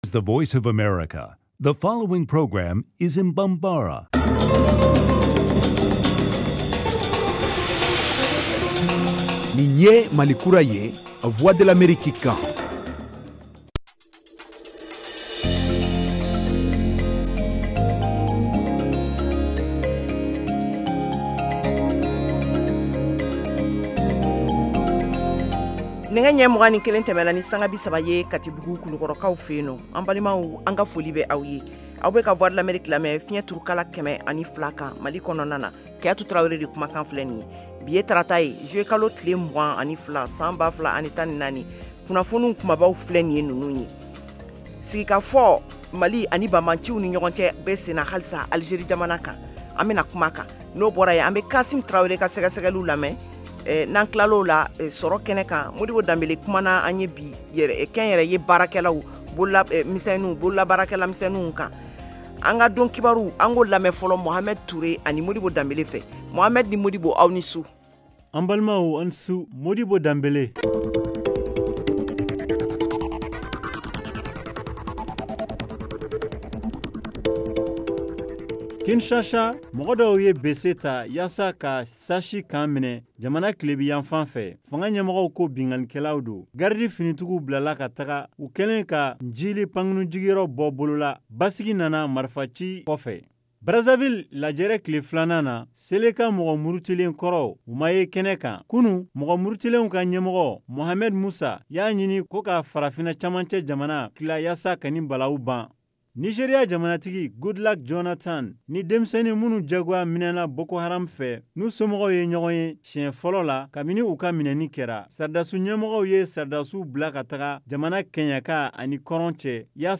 Emission quotidienne
en direct de Washington. Au menu : les nouvelles du Mali, les analyses, le sport et de l’humour.